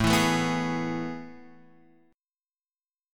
AM7sus2 chord {5 7 7 4 x 4} chord